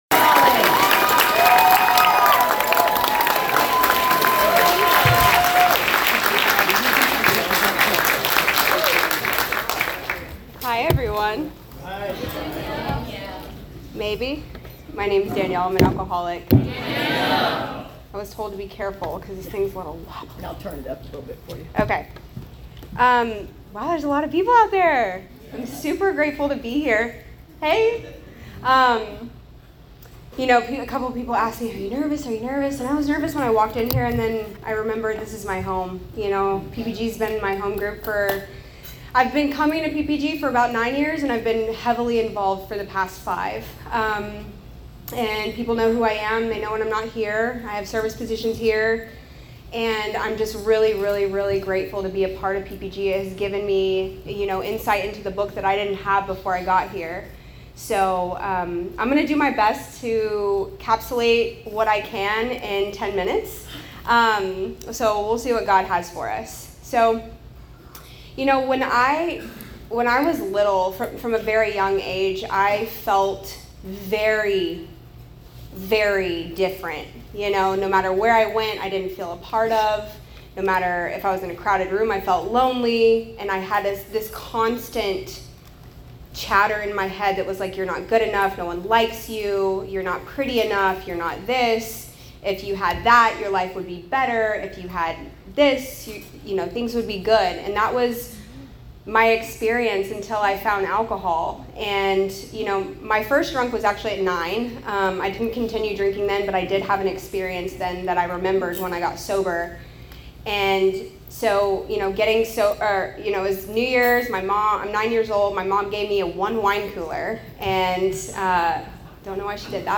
Speaker Download Links:Click the links below to download our anniversary speaker tapes.